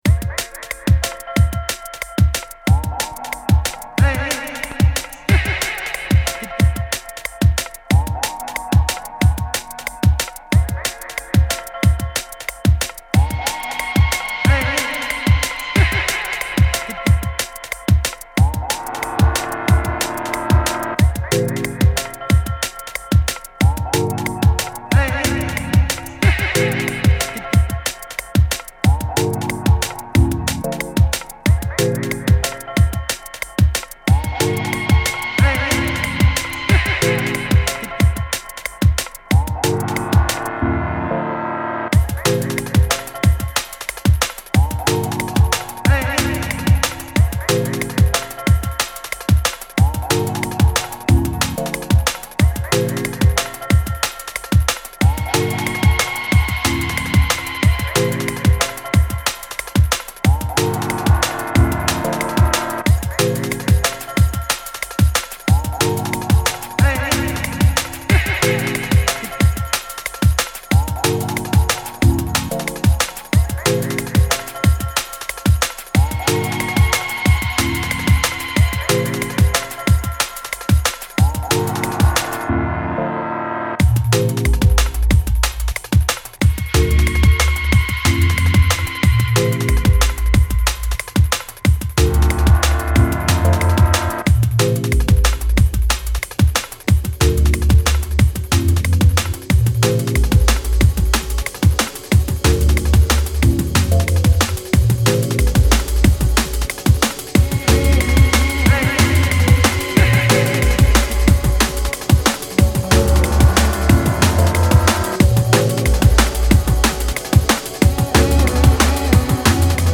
Drum and bass mix simply titled by the date I recorded it.